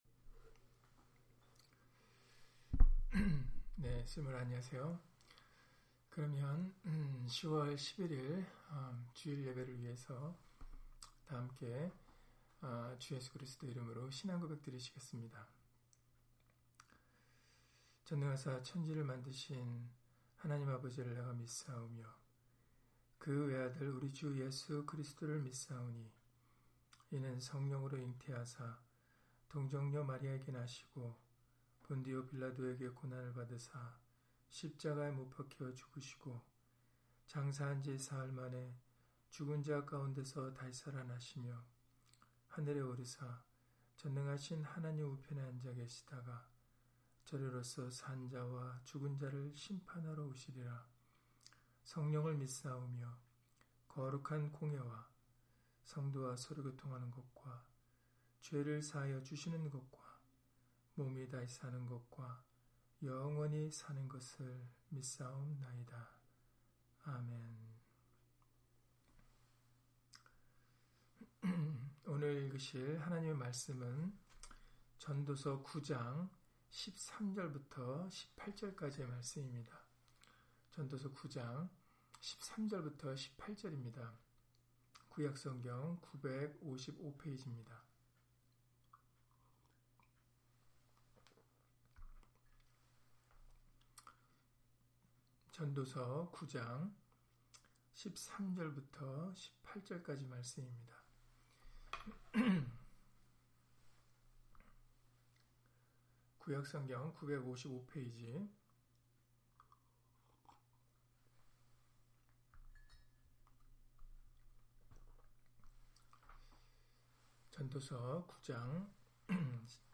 전도서 9장 13-18절 [그리스도는 하나님의 지혜] - 주일/수요예배 설교 - 주 예수 그리스도 이름 예배당